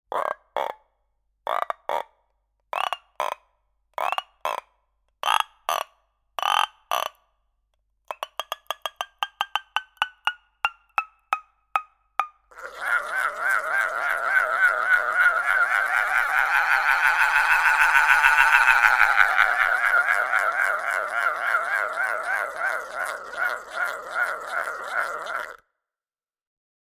This sound maker imitates the sound of the frog. It originates from shepherd’s toys.
They used to make it from walnut shell and horse tail string and wooden stick. It can also be used as a ratchet noisemaker.
You can also just hold the stick and rotate the wrist to make the rattle sound.